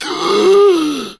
MGasp2.ogg